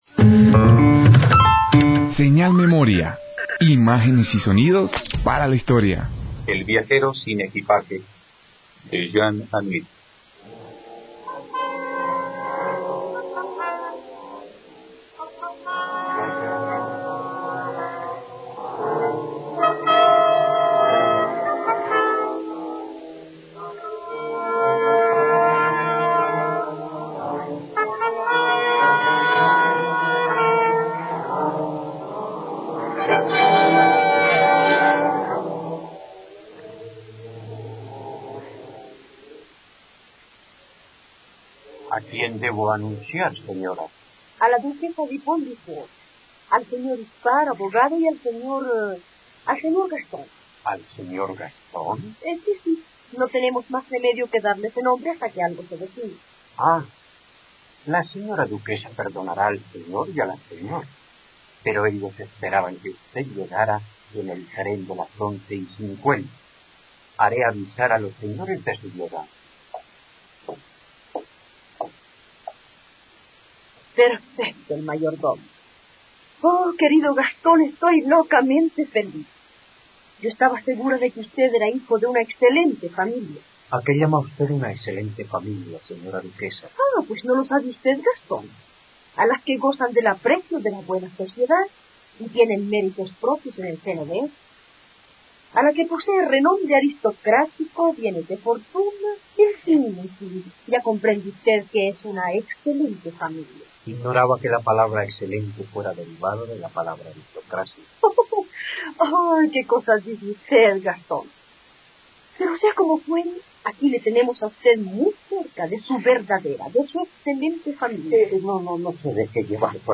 Asset ID 0 Arriba 0% Down 0% Producción El viajero sin equipaje Tags guerra radioteatro horror violencia escrúpulos Duración 58Minutos Archivo El viajero sin equipaje_web.mp3 (13.27 MB)